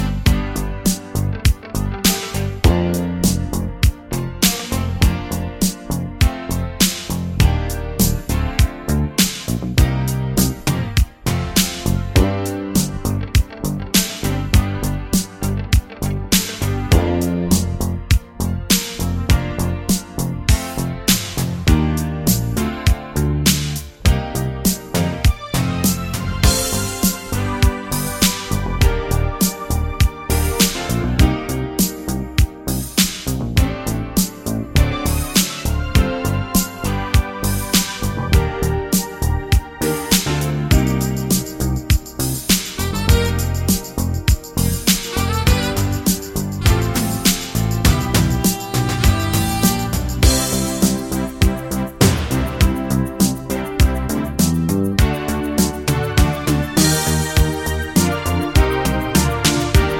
no Backing Vocals Duets 3:25 Buy £1.50